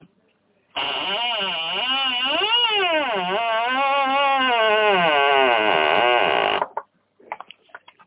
播放用AMR信念录制的笑声的吱吱门到WAV " 吱吱门WAV
Tag: 机械 石油 机械 弗利